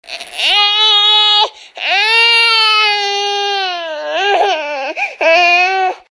(tarzan 1999) Baby Tarzan’s Cry Sound Effect Free Download
(tarzan 1999) Baby Tarzan’s Cry